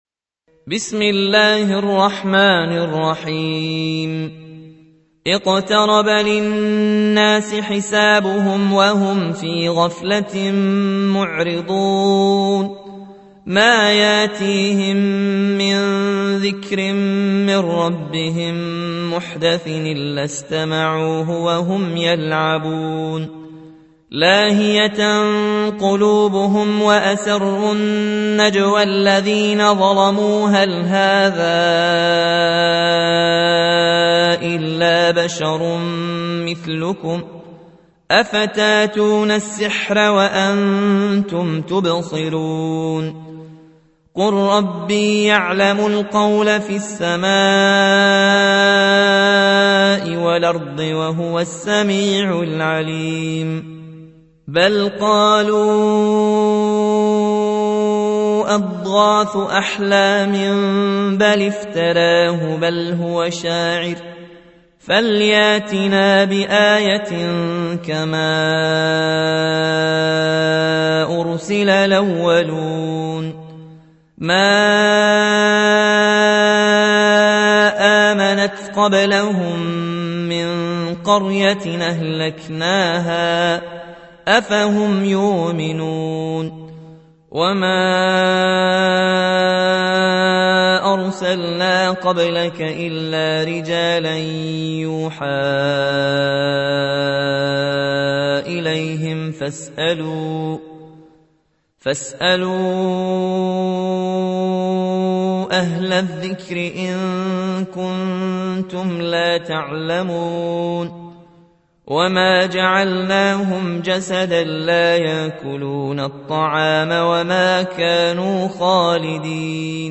تحميل : 21. سورة الأنبياء / القارئ ياسين الجزائري / القرآن الكريم / موقع يا حسين